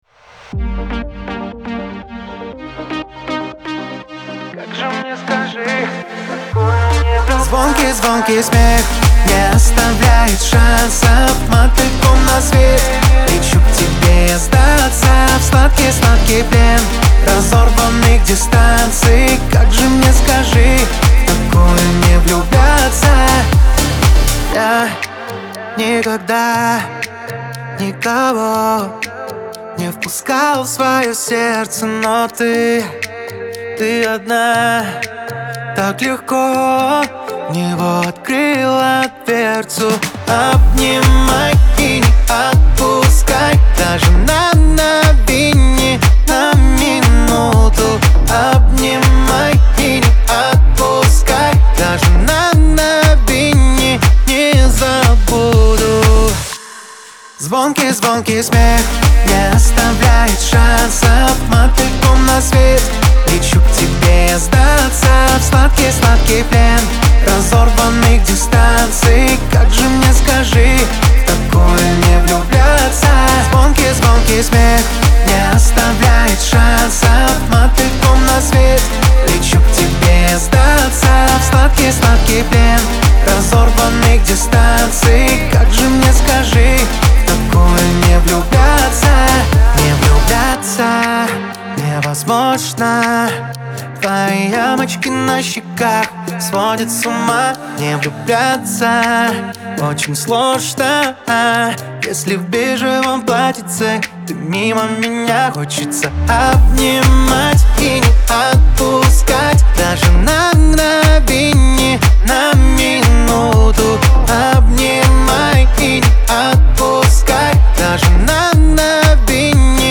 pop
дуэт